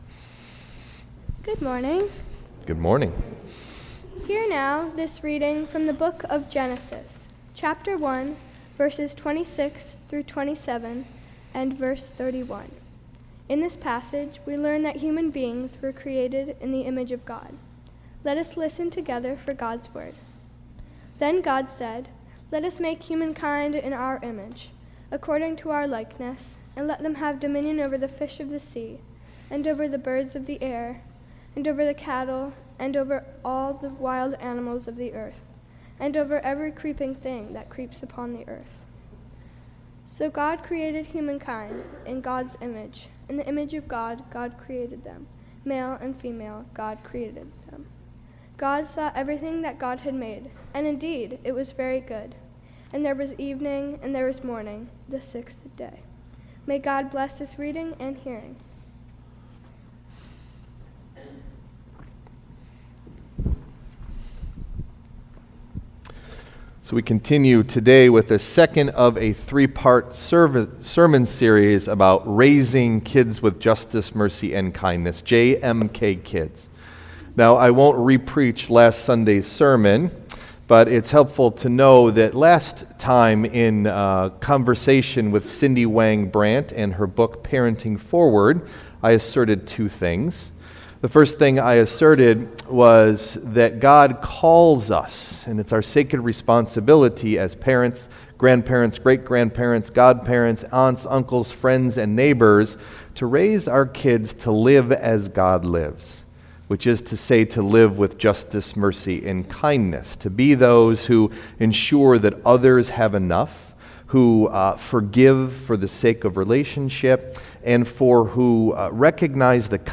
Message Delivered at: Charlotte Congregational Church (UCC)